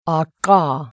CONSONANTI (NON POLMONARI)